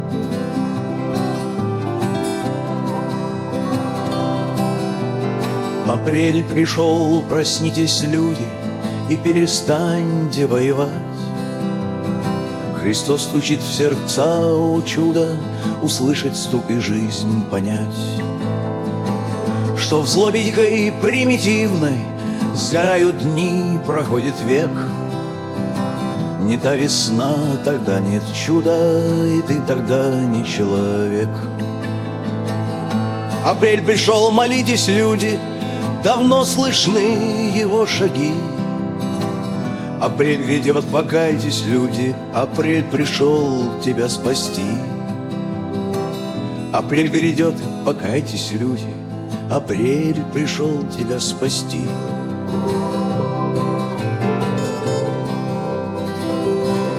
музыка, голос БГ - нейросеть Udio